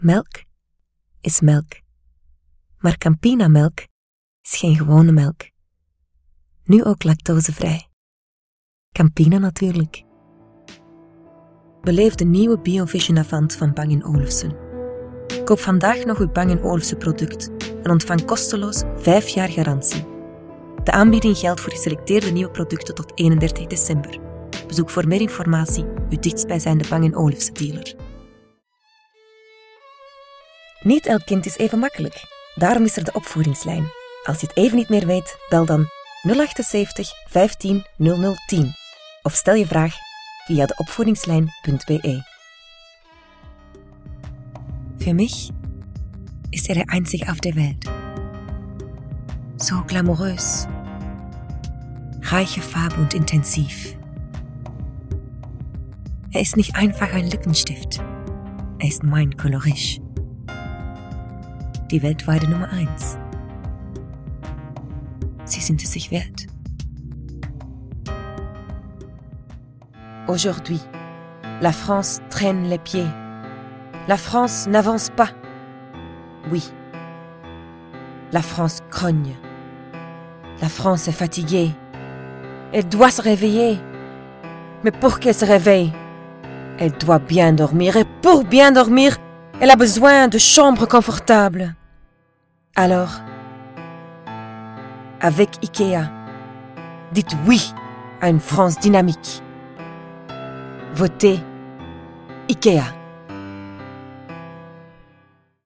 Versatile, professional Flemish Voice with own recording studio
Sprechprobe: eLearning (Muttersprache):